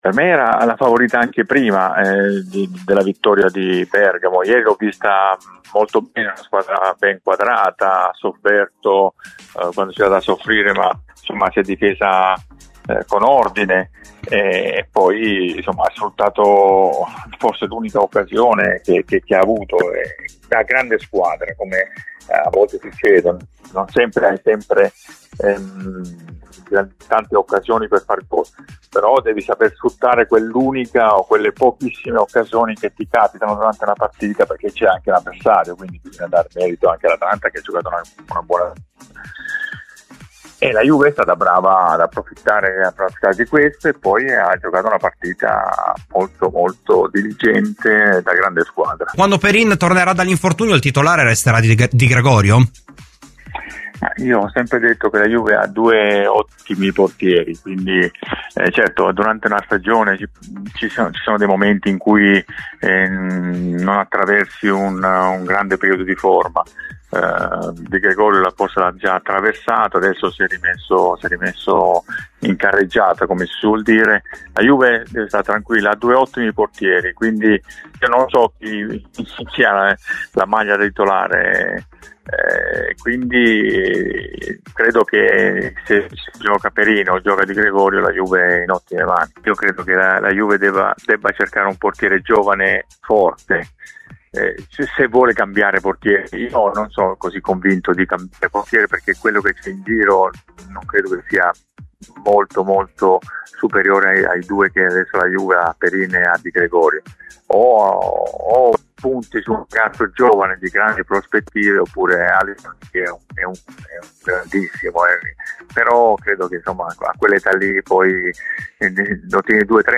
L'ex portiere Michelangelo Rampulla, ospite di "Domenica Sport" su Radio Bianconera, ha parlato del successo che la Juventus ha ottenuto sabato sera a Bergamo: “Contro l’Atalanta i bianconeri hanno giocato da grande squadra.